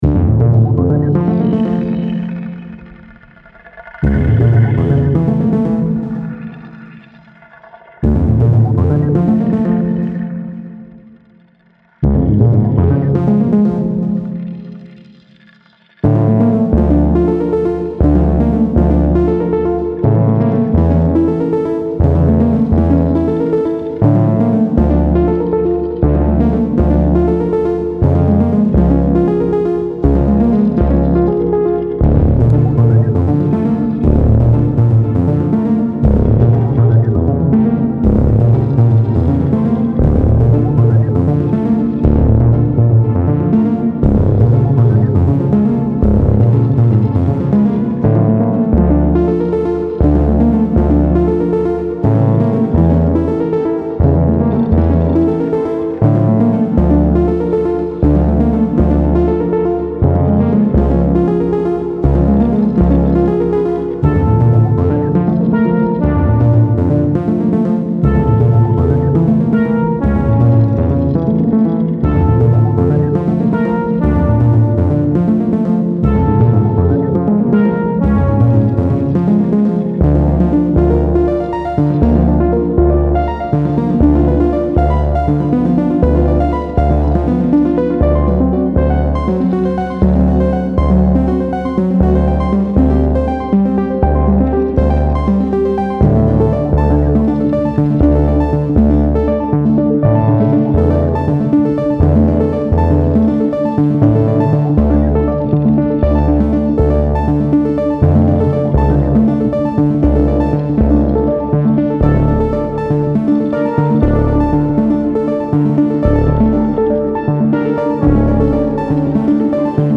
Style Style Ambient
Mood Mood Dark, Mysterious
Featured Featured Synth
BPM BPM 120